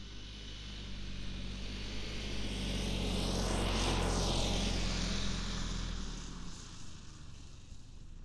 Spark Ignition Subjective Noise Event Audio File - Run 1 (WAV)